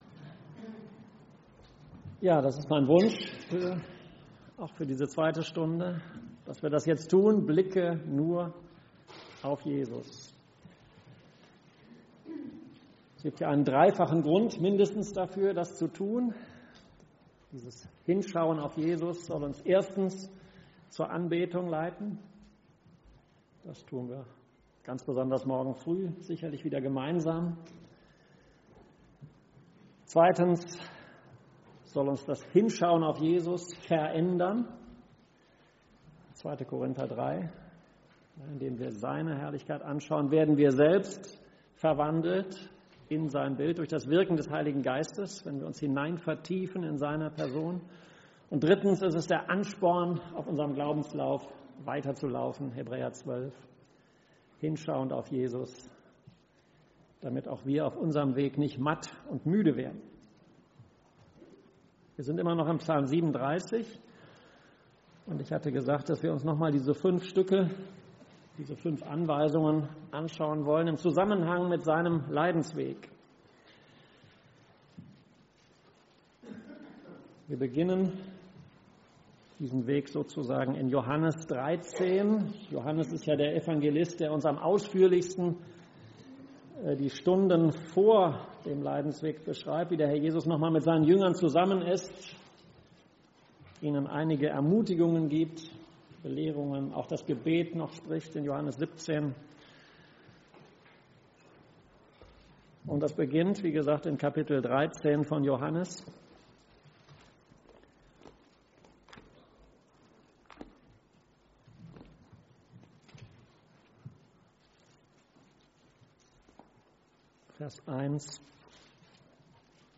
Vortrag – Psalm 37 2. Vortrag – Psalm 27